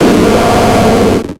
Cri d'Ursaring dans Pokémon X et Y.